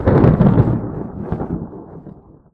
tenkoku_thunder_close03.wav